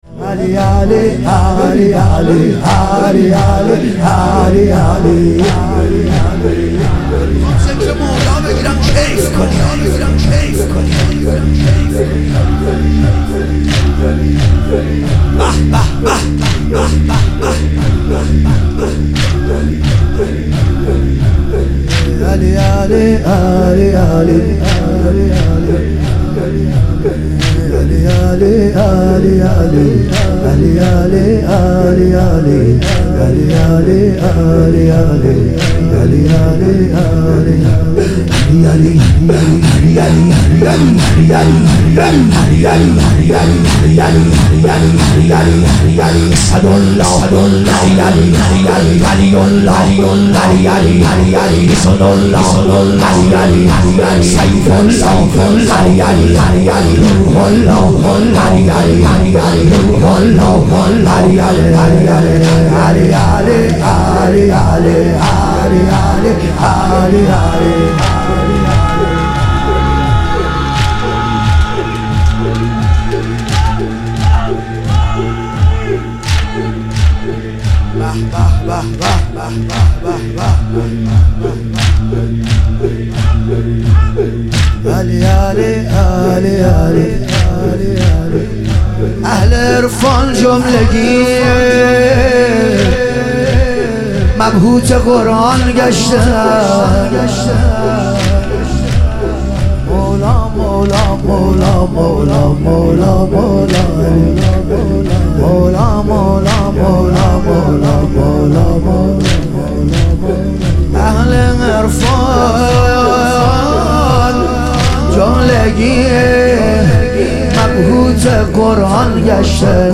شب شهادت امام حسن مجتبی علیه السلام